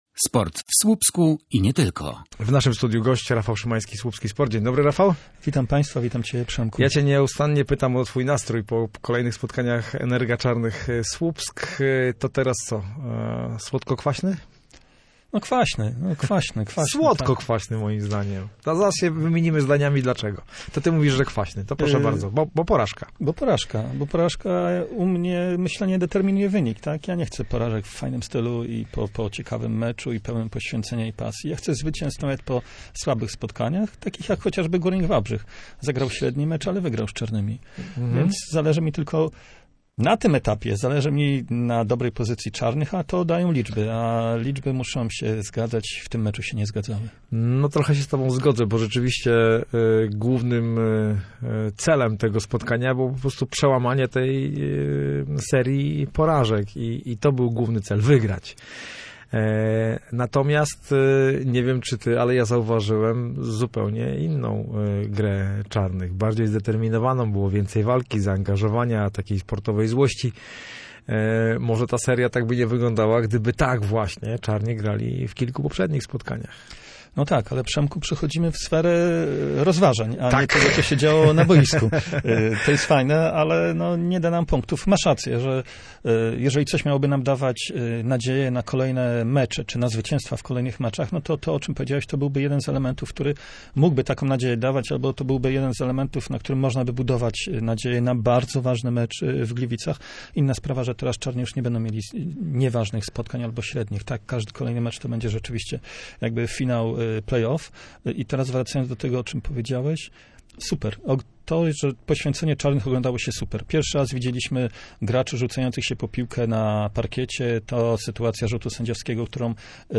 Magazyn_koszykarski_OK_5_12.mp3